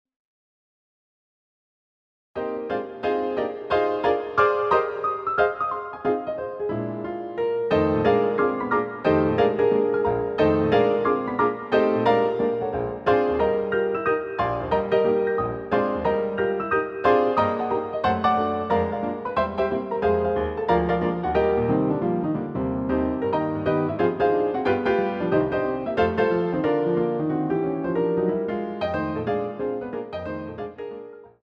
CD quality digital audio Mp3 file recorded
using the stereo sampled sound of a Yamaha Grand Piano.